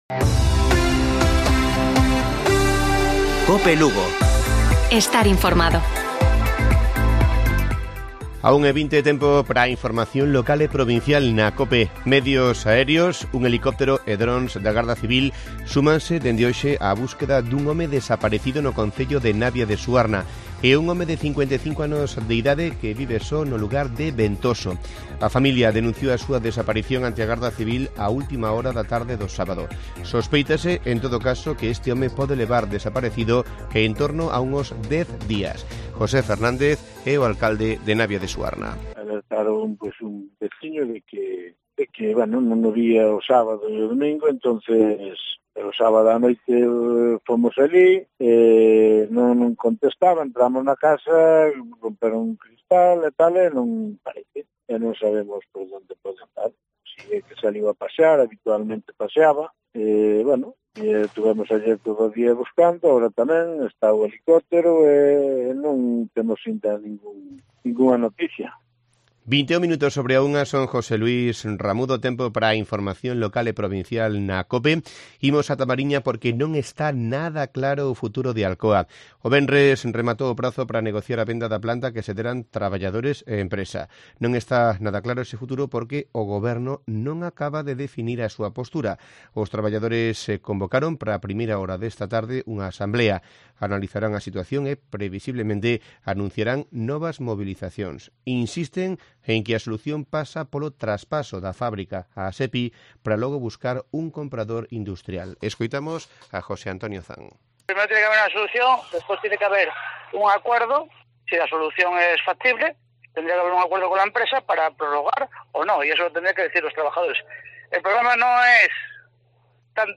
Informativo Provincial de Cope Lugo. 03 de mayo. 13:20 horas